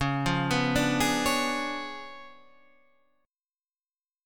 C# 7th Flat 9th